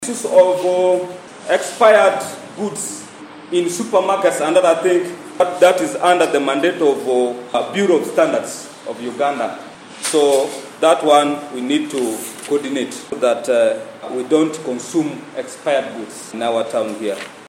In a recent council sitting on Thursday, September 28th, 2023, the Arua Central Division Council in Arua City has taken a strong stance against traders who have been selling expired goods, particularly rotten meat, in the area.
Khan Ayub, a dedicated councilor representing Kenya South, raised an alarming point during the meeting. He disclosed that expired goods were not limited to street vendors but were also prevalent in shops and supermarkets.